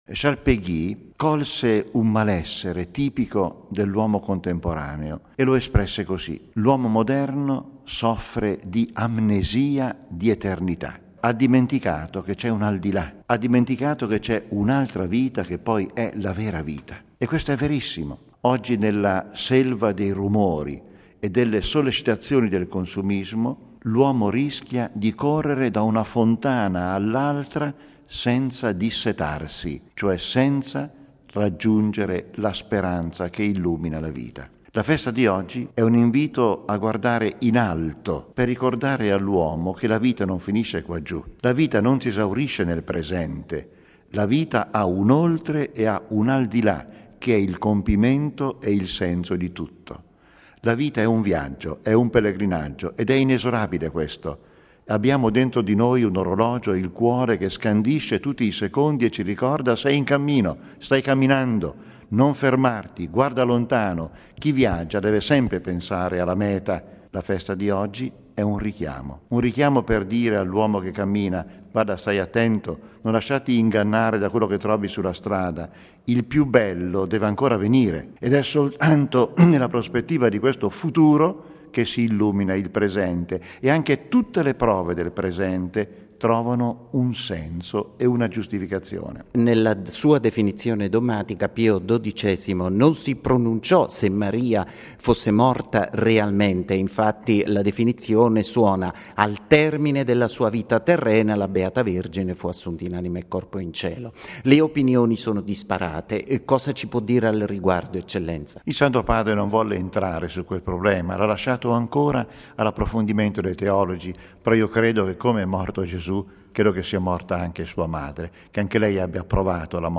Festa dell'Assunzione: il commento di mons. Comastri